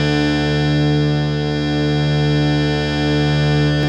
52-key01-harm-d2.wav